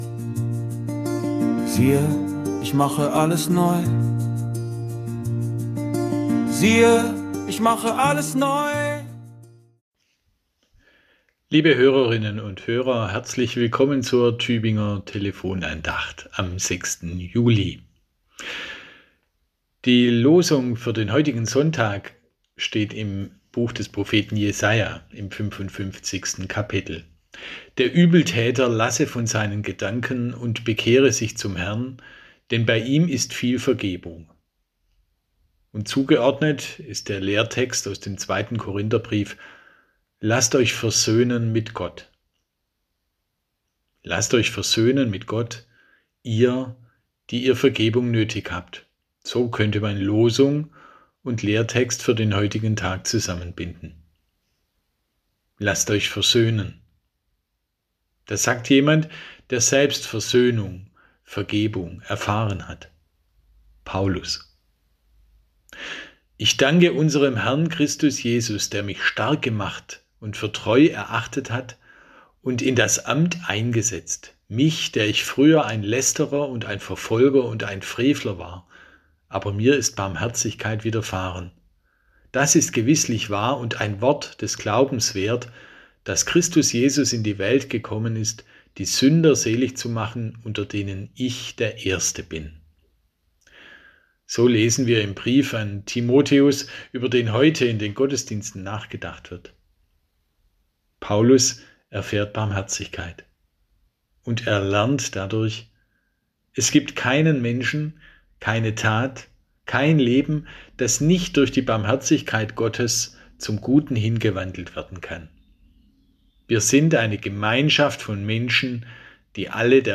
Andacht zum Wochenspruch